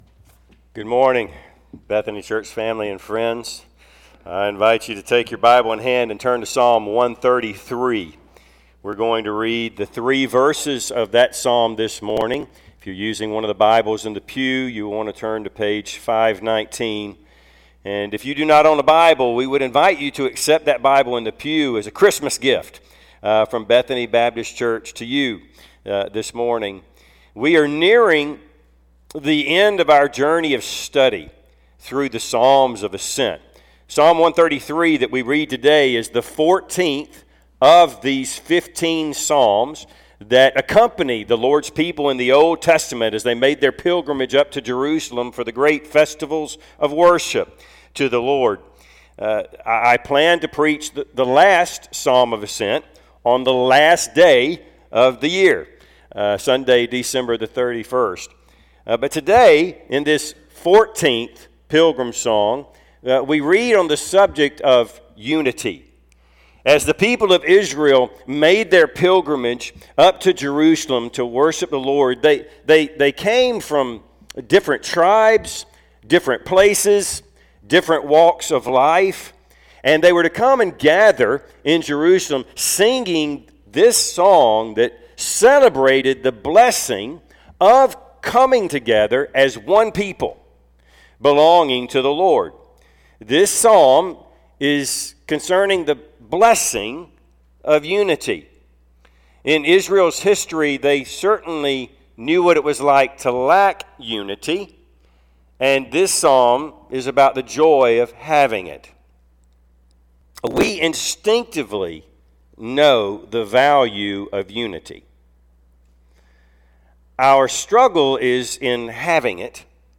Service Type: Sunday AM Topics: Christian fellowship , God's love , unity